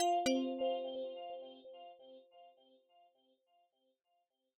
We created luxurious compositions to evoke the ever-shifting skies, with a complete sound set of immersive original music and UX sounds heards throughout the traveler's journey.
delta-error.wav